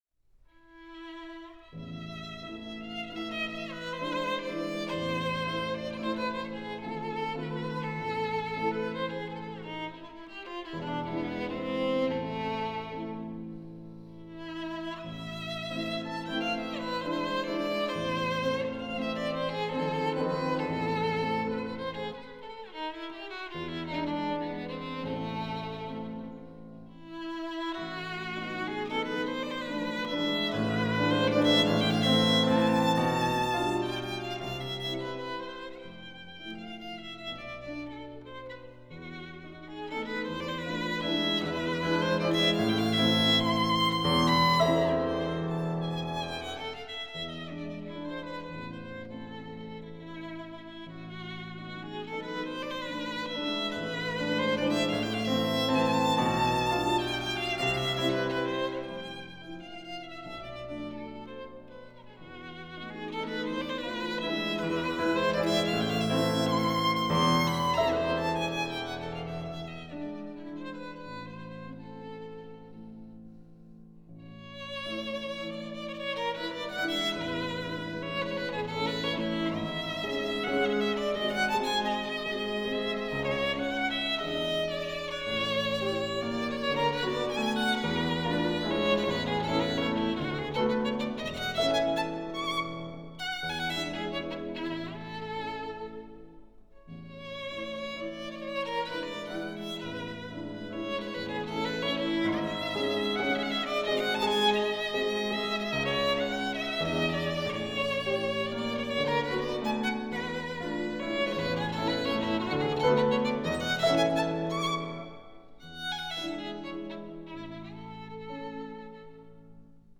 ویولن
Violin-Mazurkas-Op.-67-Mazurka-No.-4-in-A-Minor-Frederic-Chopin.mp3